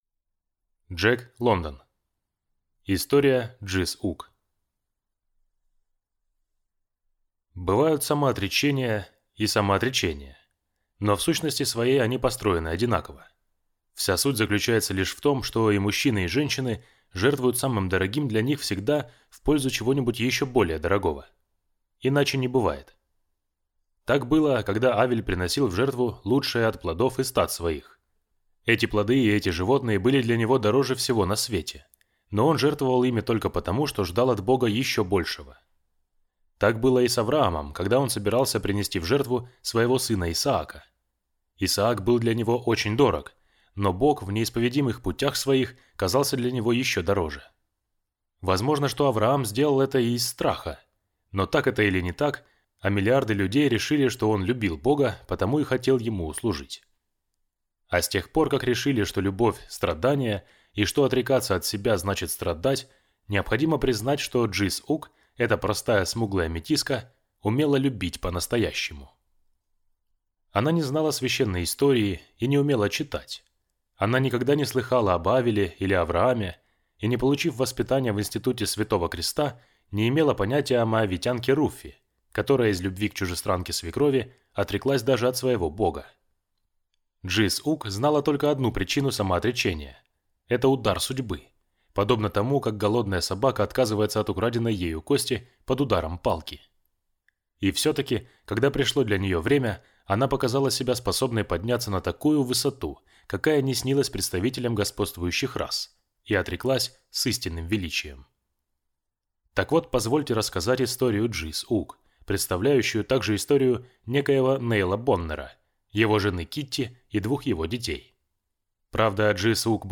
Аудиокнига История Джис-Ук | Библиотека аудиокниг